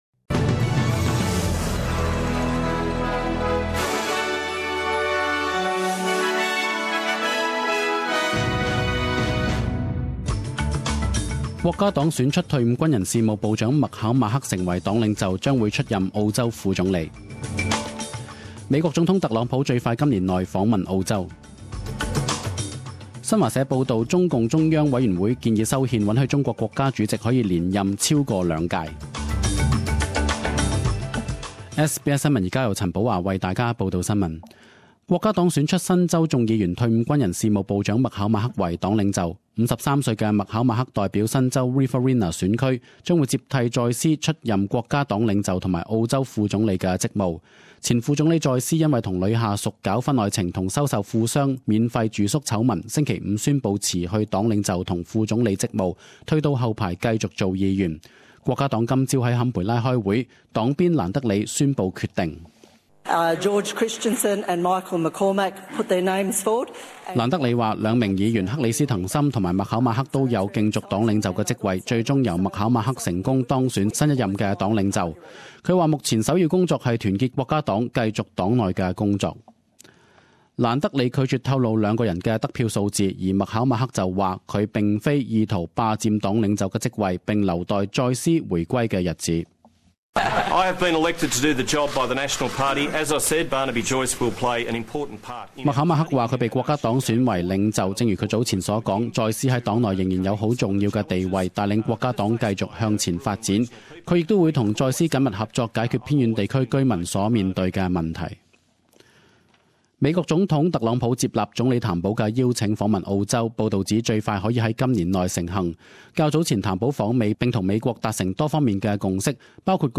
Cantonese News